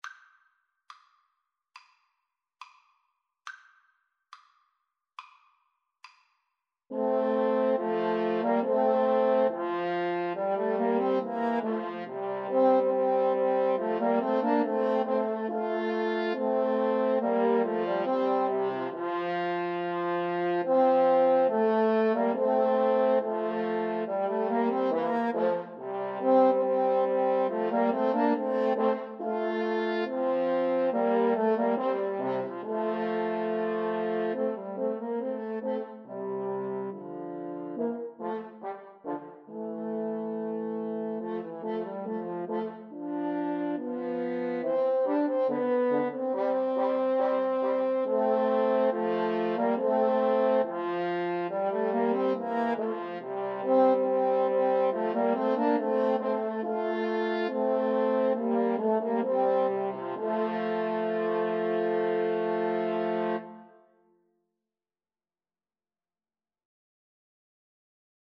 Free Sheet music for French Horn Trio
Play (or use space bar on your keyboard) Pause Music Playalong - Player 1 Accompaniment Playalong - Player 3 Accompaniment reset tempo print settings full screen
Andante maestoso
C major (Sounding Pitch) (View more C major Music for French Horn Trio )
Classical (View more Classical French Horn Trio Music)